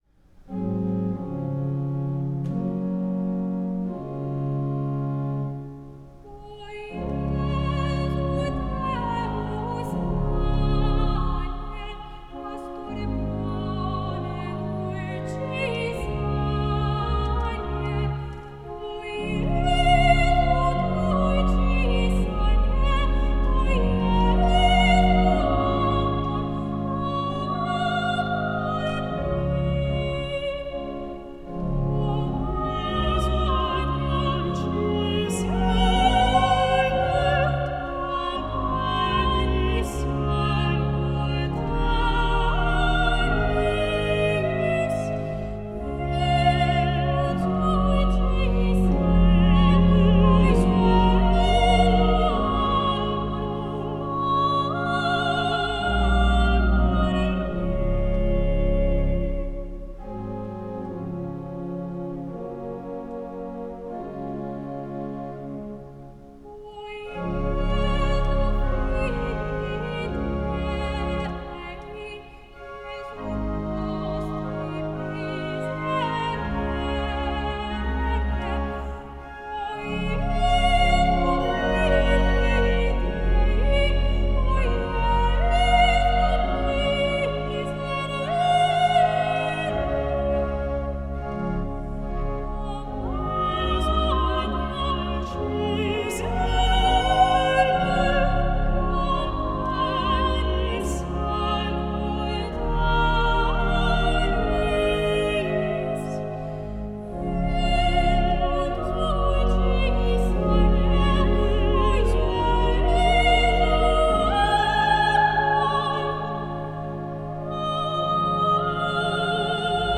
Recorded at St. Paul’s R.C. Church, Cambridge, MA, August 2014